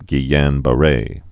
(gē-yănbə-rā)